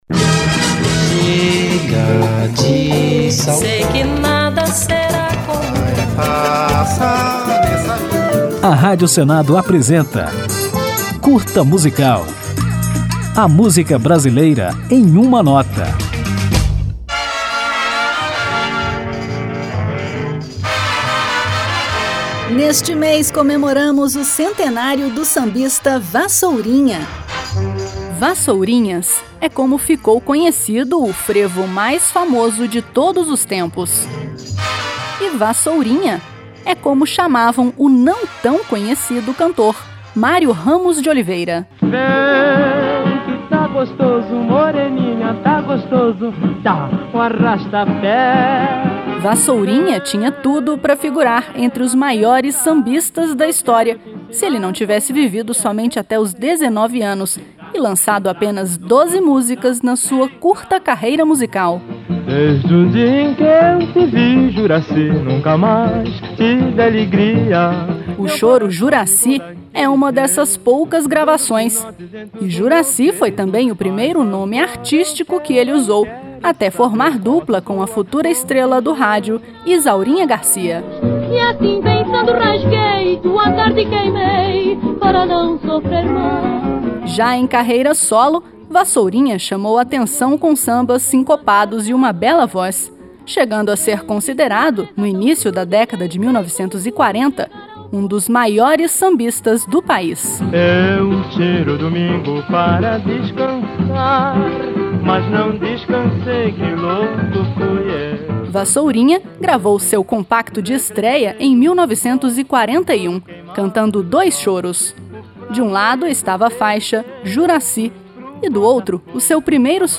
Ao final da homenagem, que conta a curiosa história deste artista que marcou o Brasil no início dos anos 1940, ouviremos a canção Emília, o maior sucesso de Vassourinha.